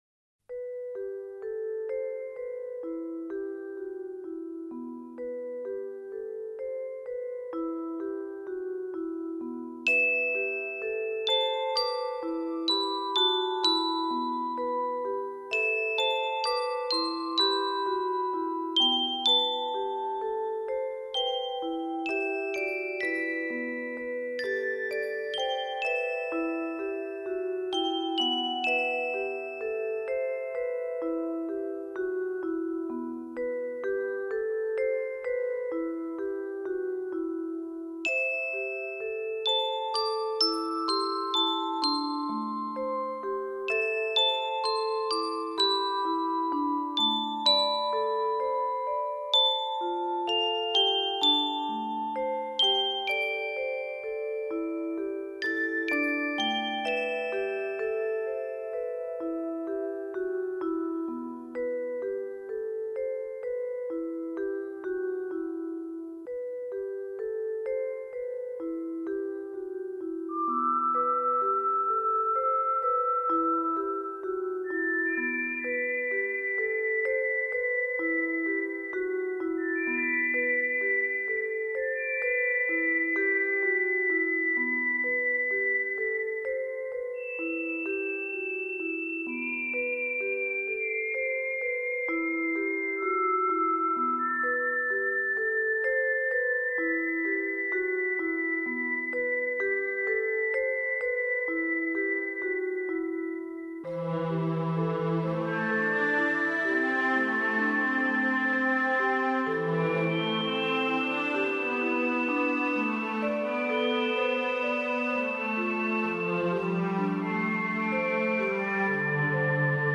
水晶琴闪烁着最清心无欲的音符，以简单、真朴的旋律，
点点清韵，